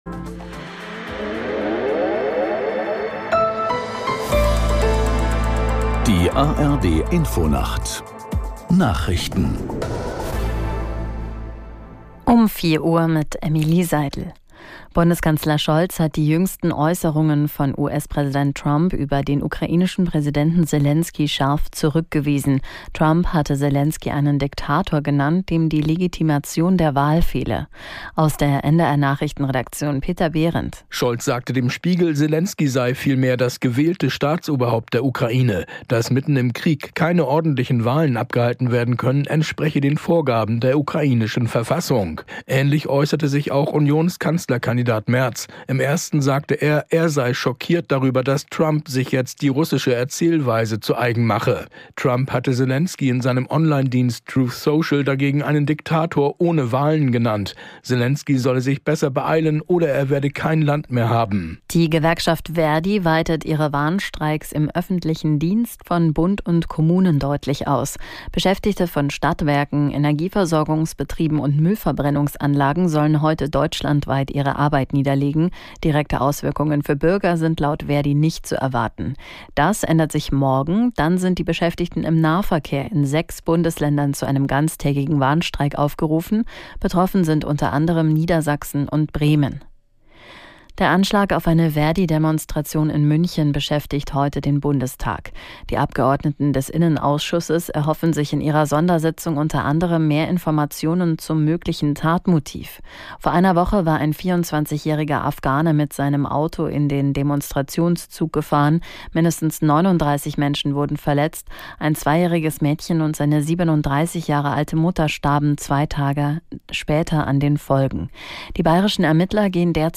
1 Nachrichten 6:03